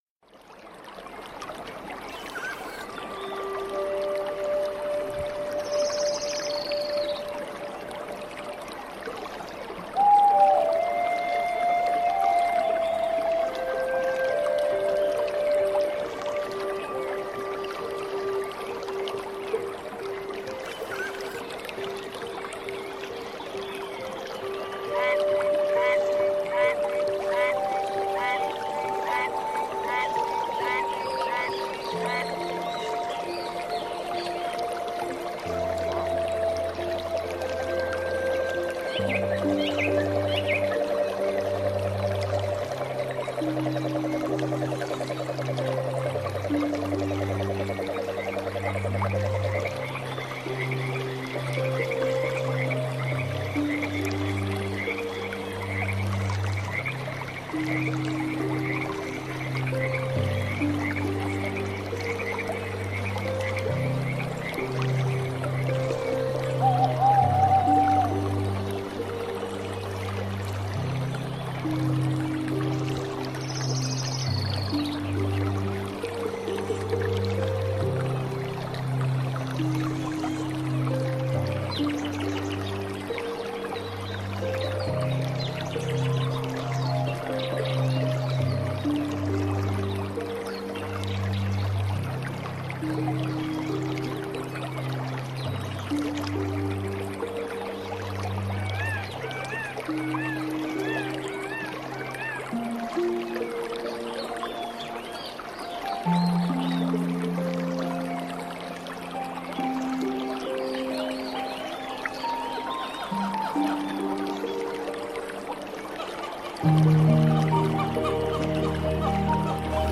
Relaxing Music Beautiful Flute Meditation Birds Singing Water So.mp3